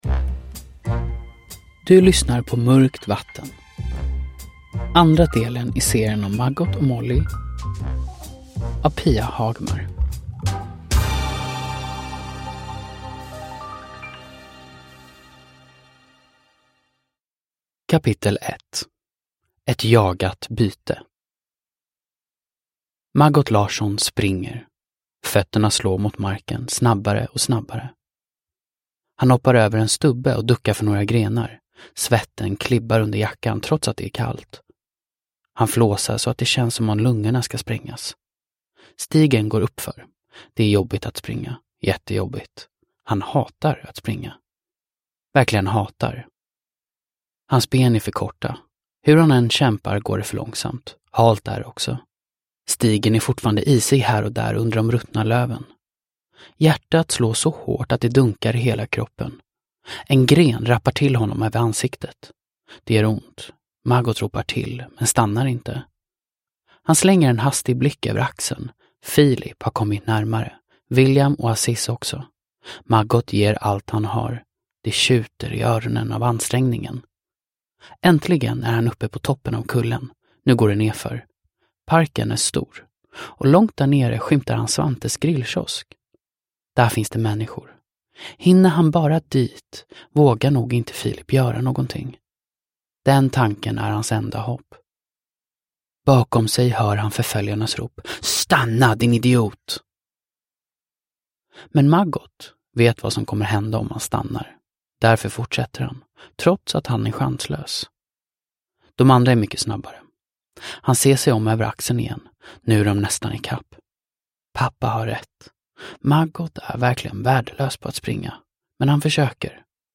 Mörkt vatten – Ljudbok